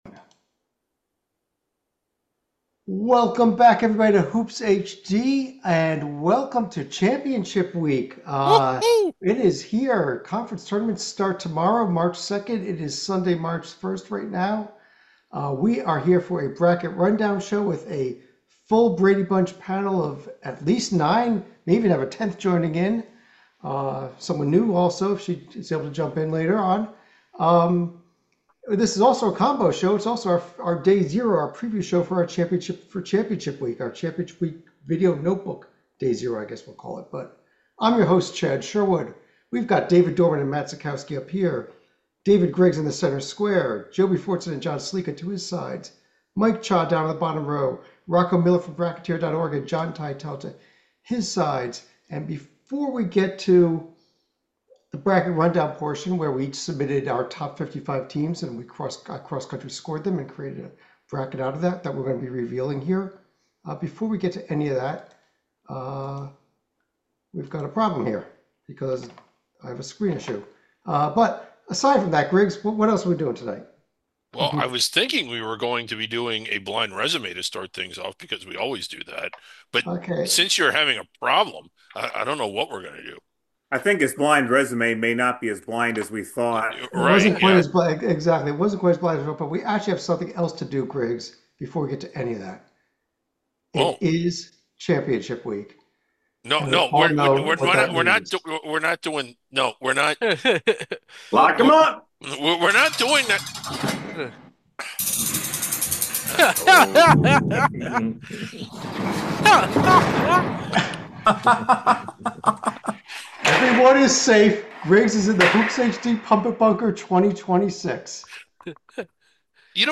They discuss, assess, and debate each team as they are revealed. See who the #1 seeds are, who the rest of the protected seeds are, who is on the bubble, and who just missed it.
And for all you radio lovers, below is an audio only version of the show.